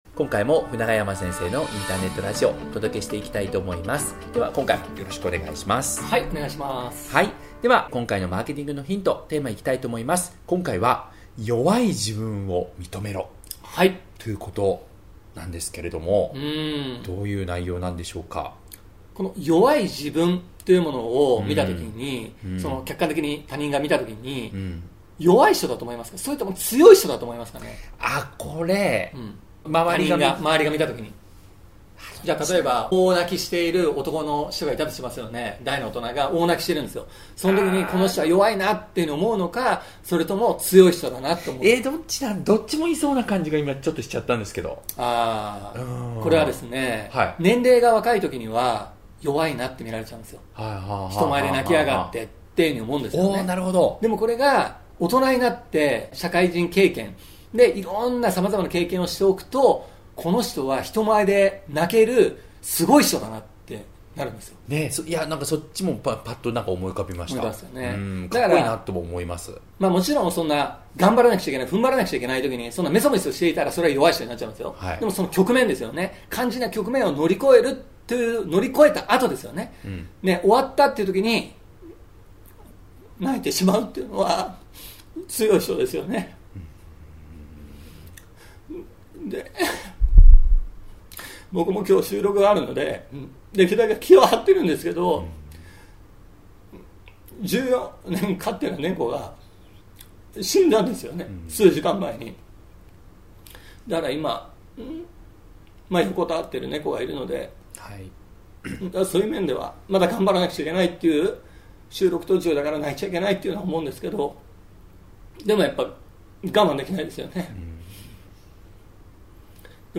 Podcast的錄音。我打開聽之後直接感受到他的悲傷，讓我不禁想像若有一天與愛貓道別，是否我也只能像這本書的結尾一樣，無法收尾呢？